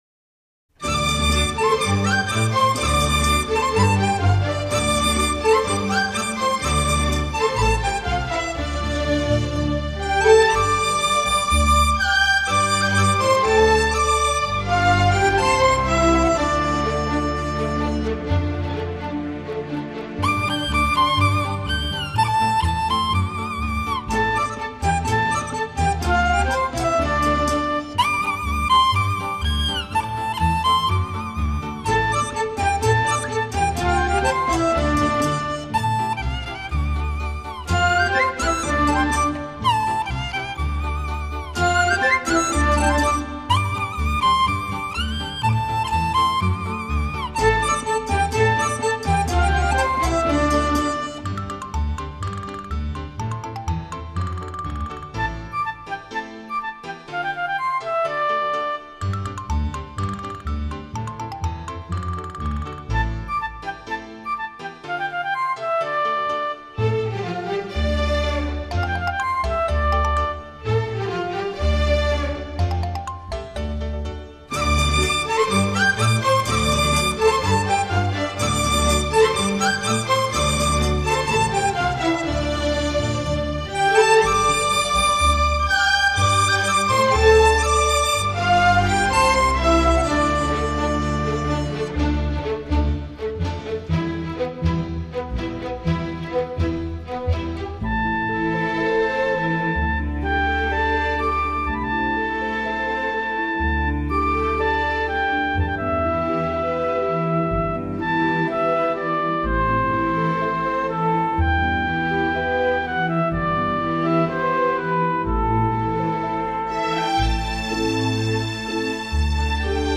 专辑类型：纯音乐